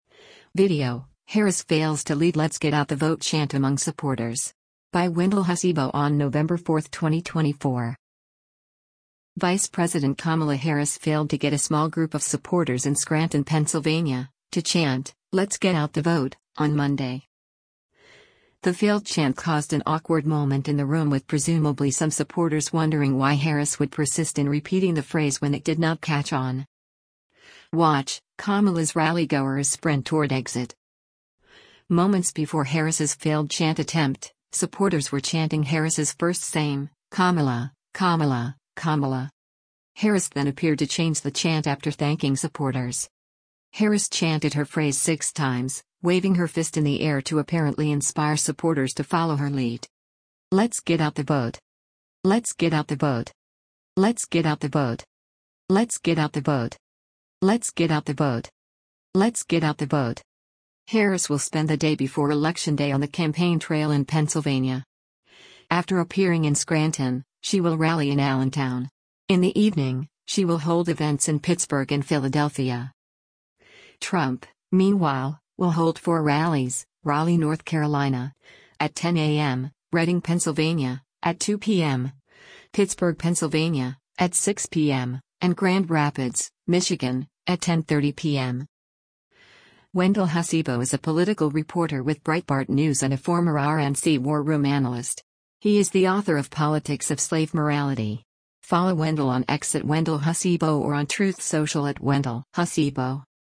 Vice President Kamala Harris failed to get a small group of supporters in Scranton, Pennsylvania, to chant, “Let’s get out the vote,” on Monday
The failed chant caused an awkward moment in the room with presumably some supporters wondering why Harris would persist in repeating the phrase when it did not catch on.
Moments before Harris’s failed chant attempt, supporters were chanting Harris’s first same: “Kamala, Kamala, Kamala!”
Harris chanted her phrase six times, waving her fist in the air to apparently inspire supporters to follow her lead: